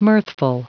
Prononciation du mot mirthful en anglais (fichier audio)
Prononciation du mot : mirthful